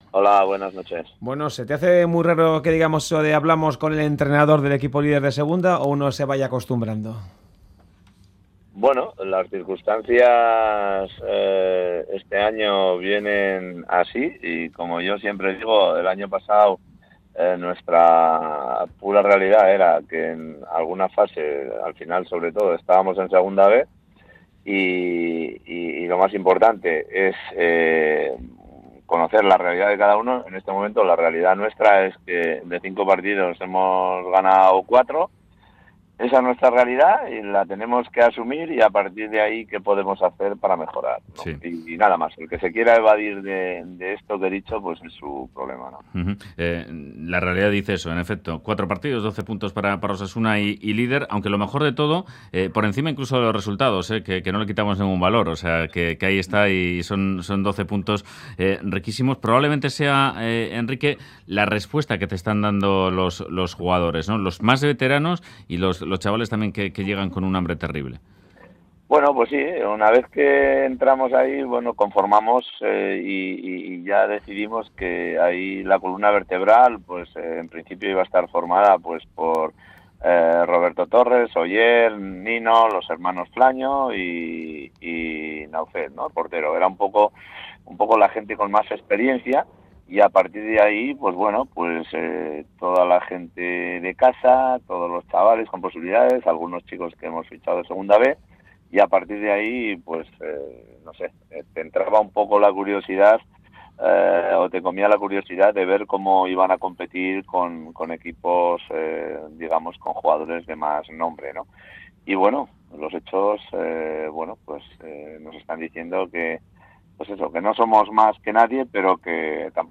En Fuera de Juego hemos hablado con Enrique Martín Monreal, líder de segunda con Osasuna